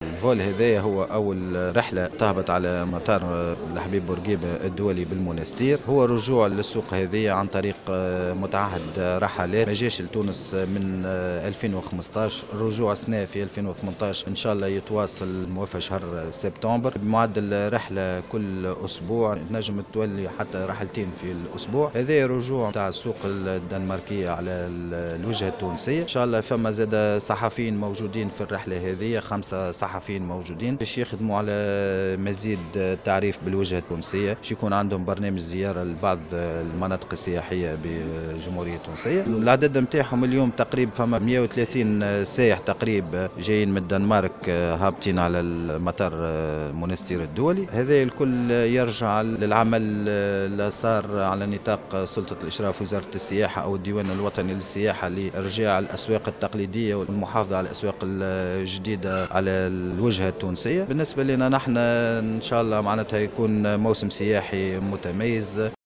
أكد فواز بن حليمة المندوب الجهوي للسياحة بالمنستير في تصريح لمراسل الجوهرة"اف ام'" اليوم الإثنين وصول 130 سائحا دنماركيا إلى مطار المنستير الدولي.